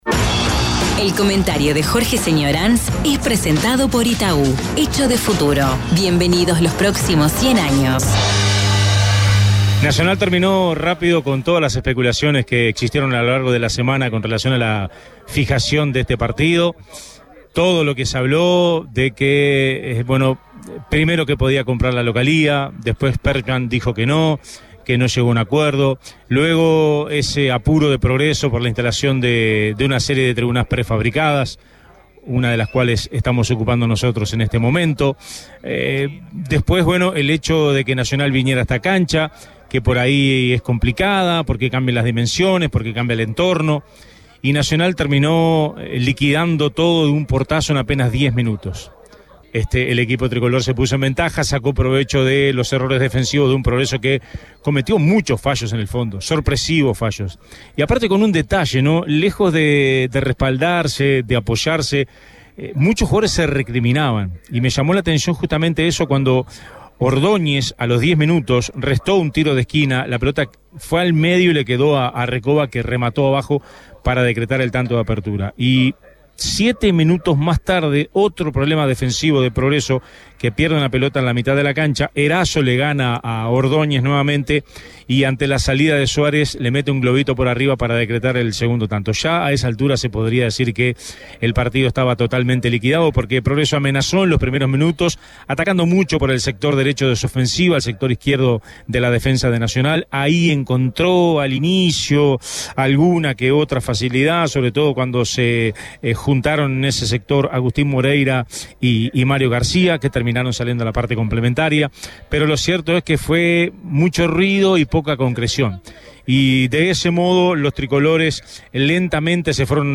Periodístico deportivo